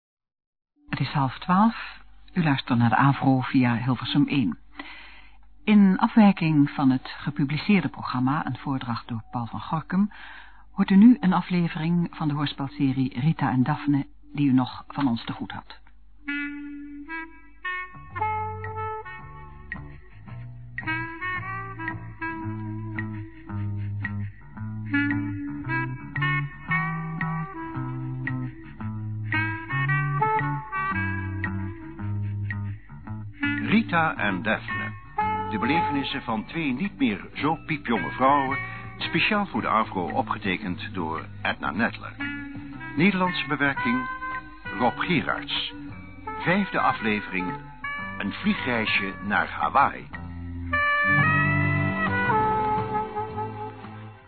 De omroepster doet verslag, al zegt ze niet waarom.